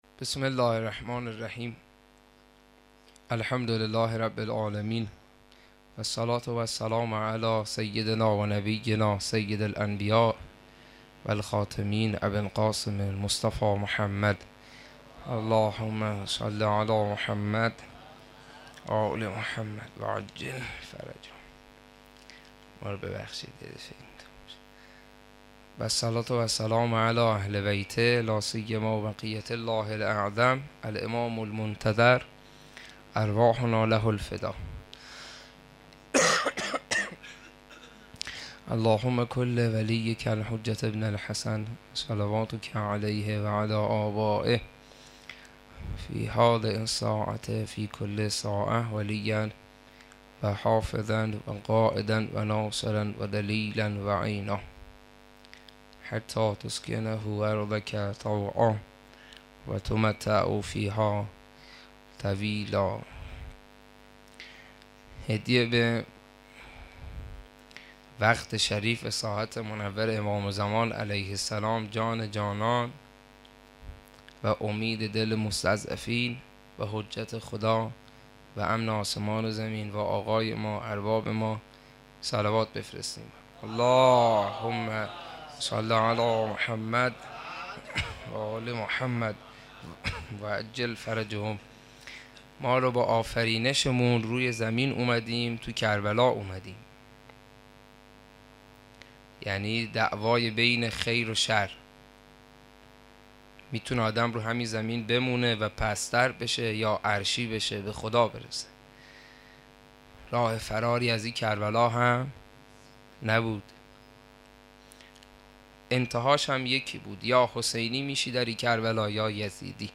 صوت شب هشتم محرم92 هیئت فاطمیون کازرون
سخنرانی و روضه